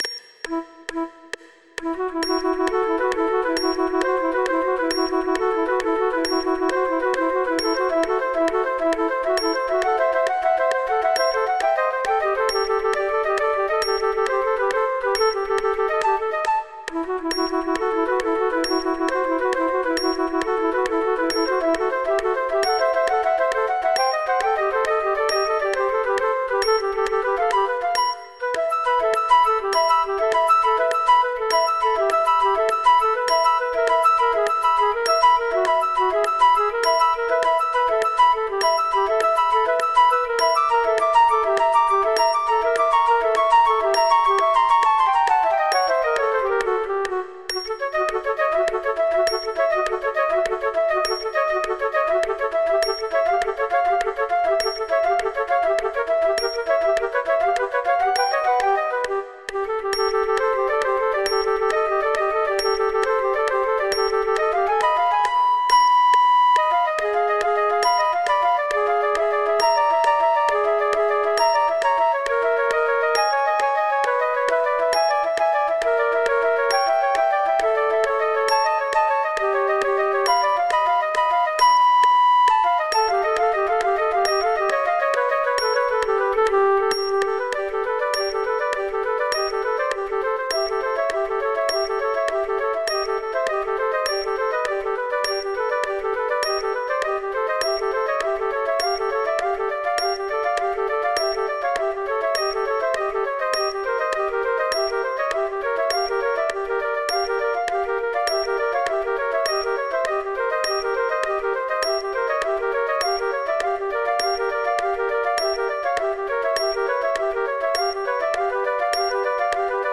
This trio for three flutes is full of aural illusions.
Various practice tracks are available in MP3 form.
With metronome clicks (and apologies for a strange metronome artefact right at the beginning),
with the first flute missing there are slow,